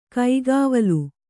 ♪ kaigāvalu